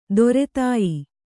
♪ dore tāyi